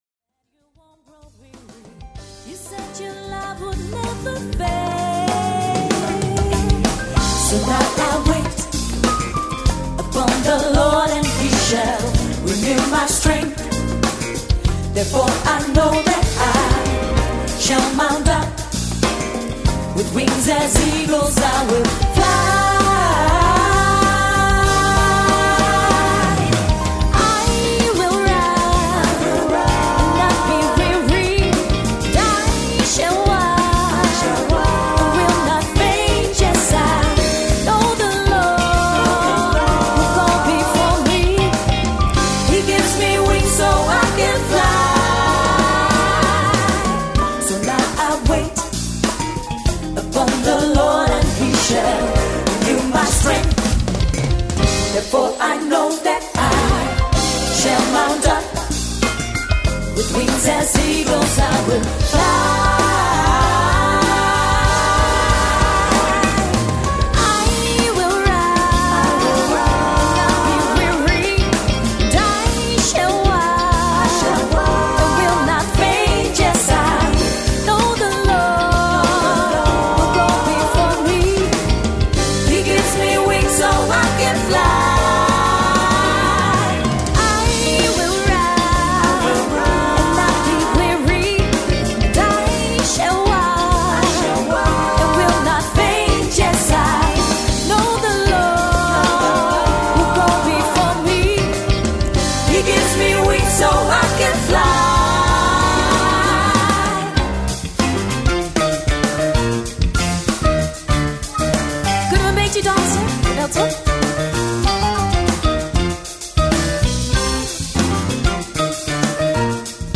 Genre Live Worship